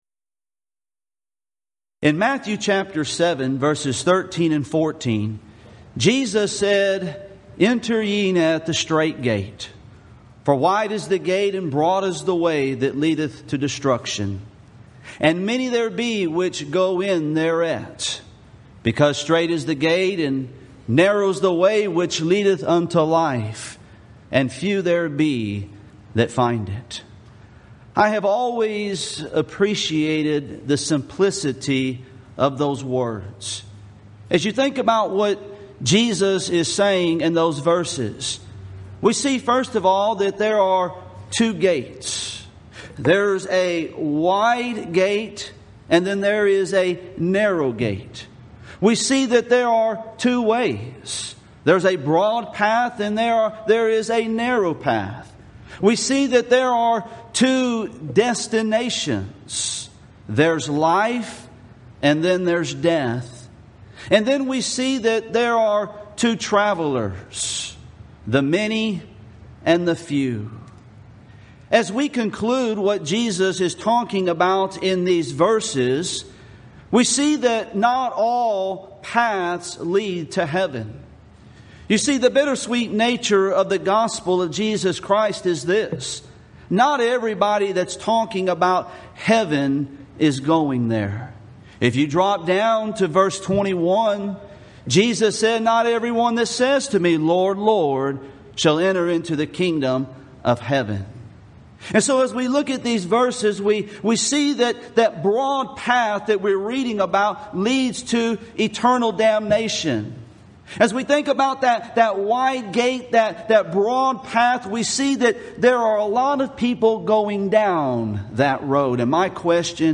Event: 2015 South Texas Lectures
lecture